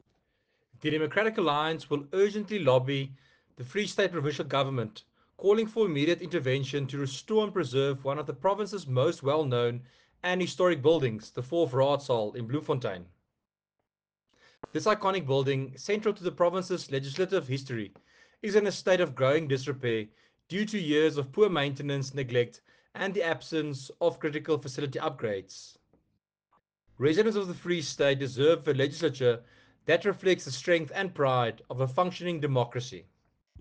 Afrikaans soundbites by Werner Pretorius MPL and